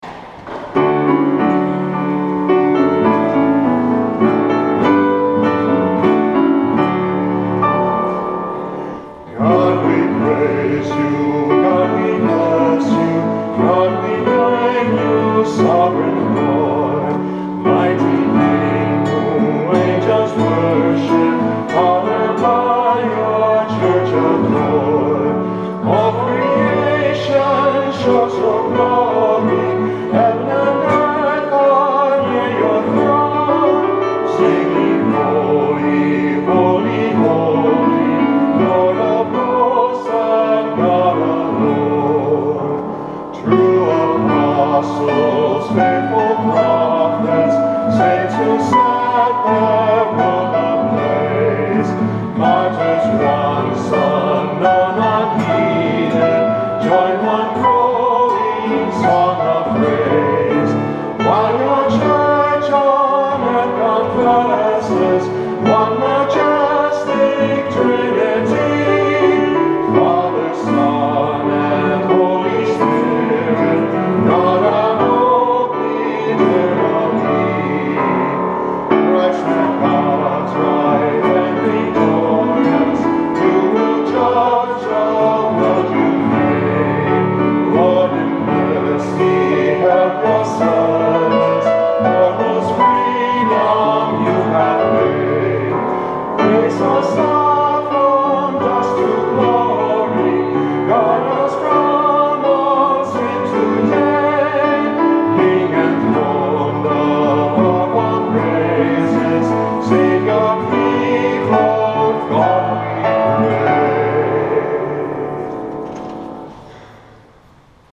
Our small ensemble leads the congregation in singing verses 1, 2 and 4 of
at the 11:30 Mass